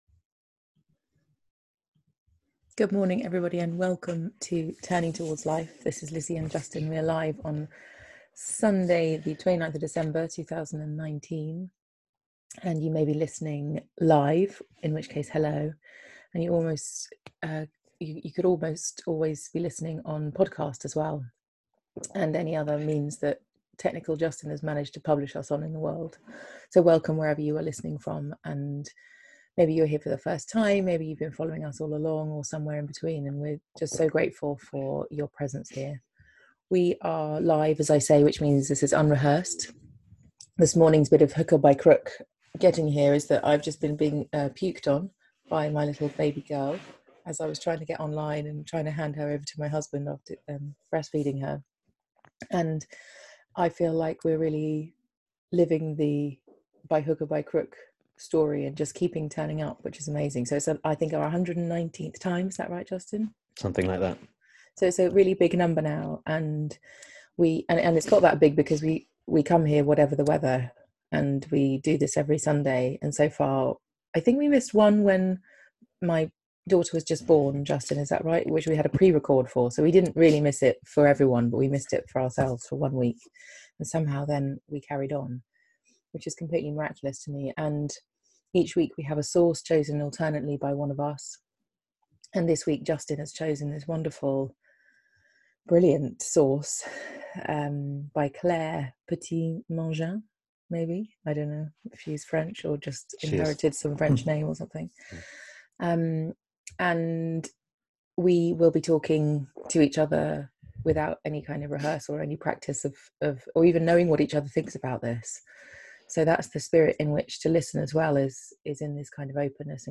A conversation about the ways we separate ourselves from life, and how we might return
a weekly live 30 minute conversation hosted by Thirdspace